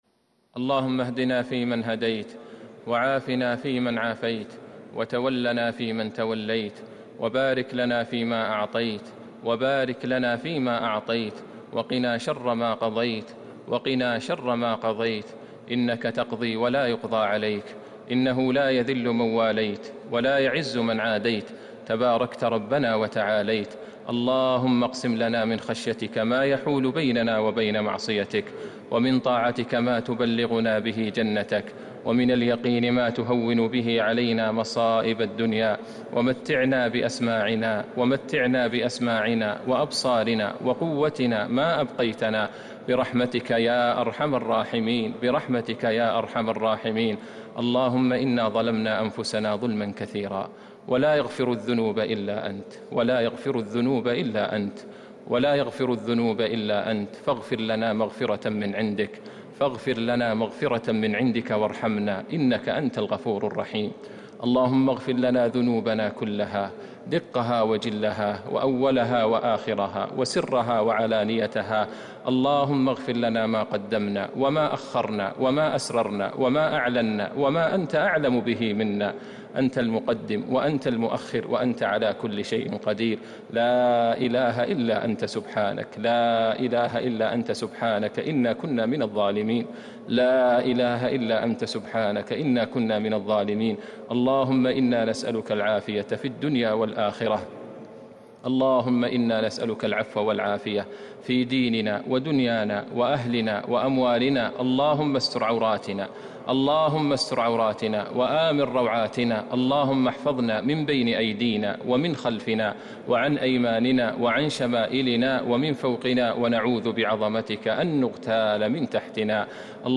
دعاء القنوت ليلة 5 رمضان 1441هـ > تراويح الحرم النبوي عام 1441 🕌 > التراويح - تلاوات الحرمين